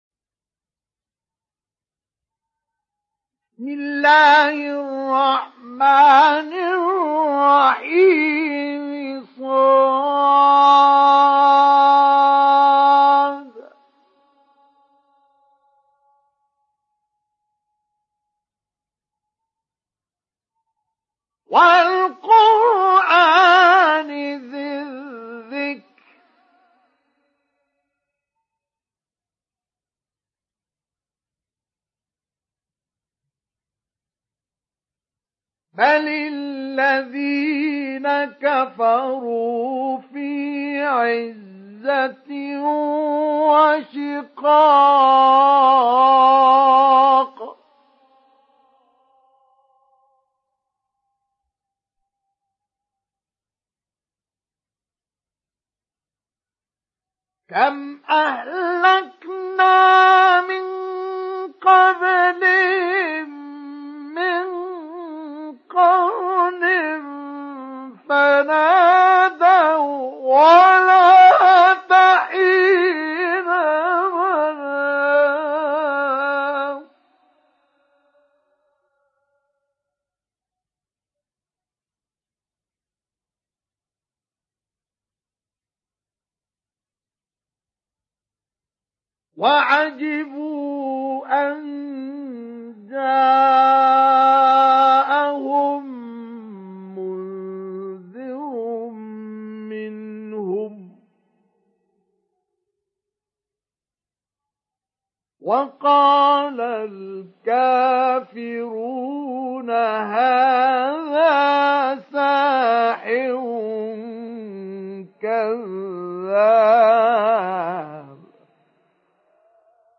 Download Surat Sad Mustafa Ismail Mujawwad